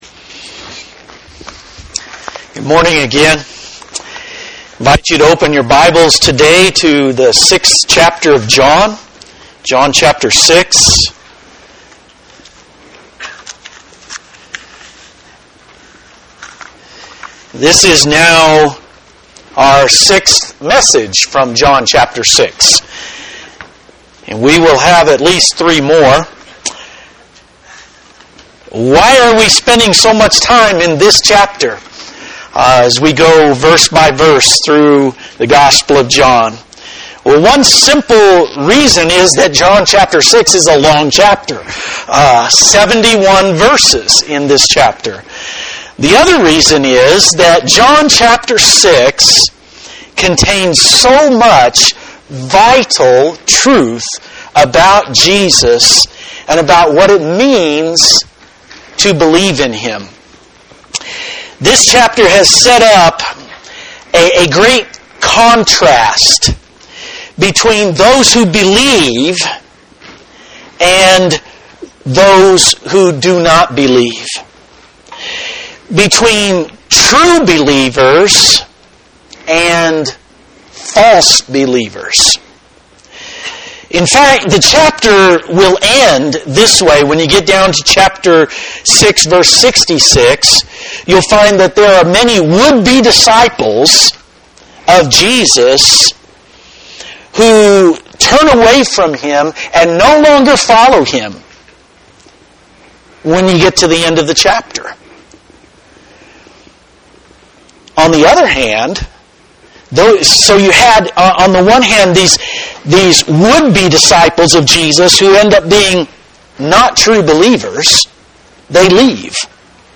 Grumbling and Grace John 6:41-47 This is now our sixth message from John 6 and we will have at least three more.